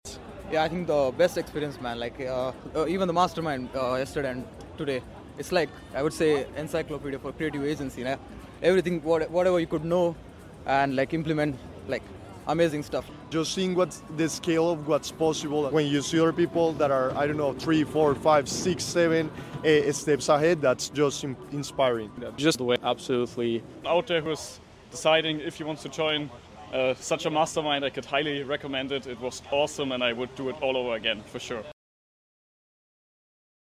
If you’re considering a mastermind, this testimonial will leave you eager to embrace the opportunity.